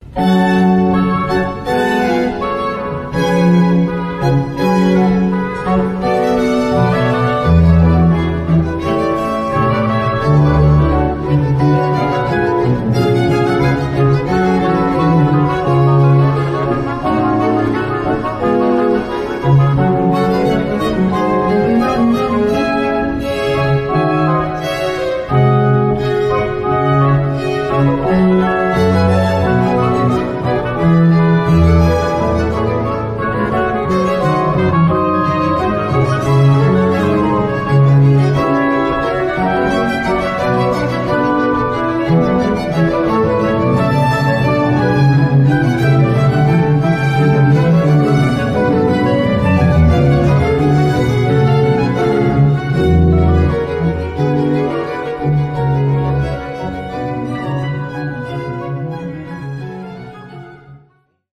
BACH, cantate bwv 47, 01 quatuor - BORGIANNI, jesus au temple.mp3